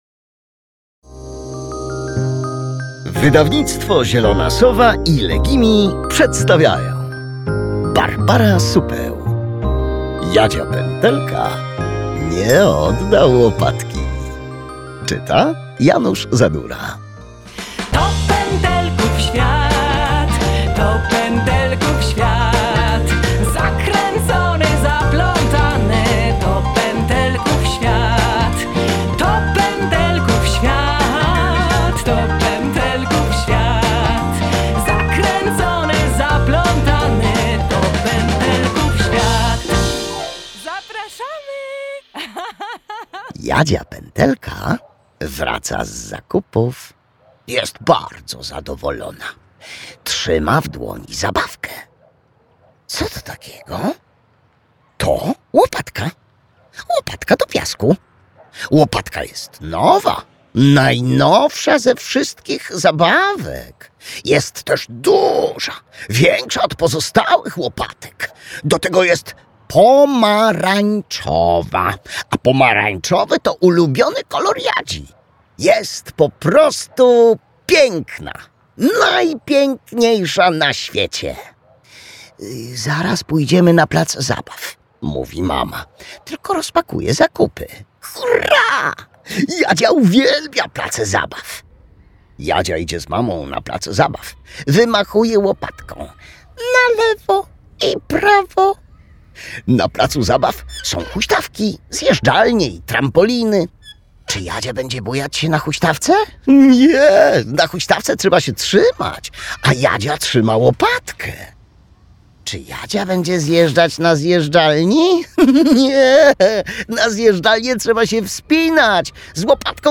Jadzia Pętelka nie odda łopatki - Supeł Barbara - audiobook + książka